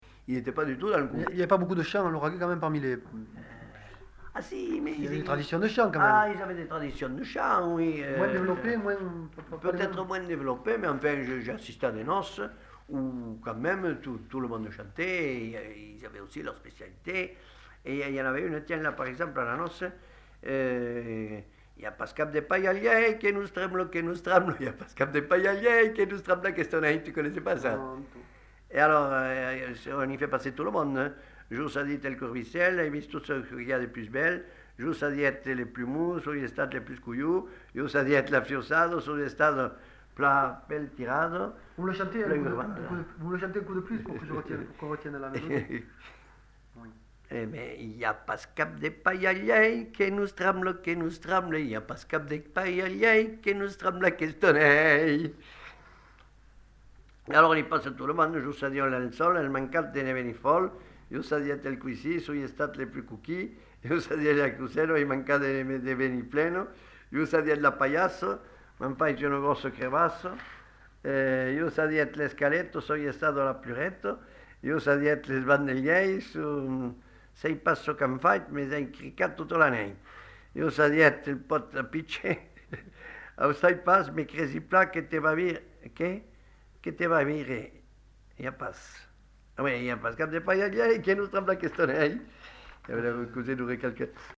Aire culturelle : Lauragais
Genre : chant
Effectif : 1
Type de voix : voix d'homme
Production du son : chanté ; lu ; récité
Description de l'item : version ; 7 c. ; refr.
Classification : énumératives diverses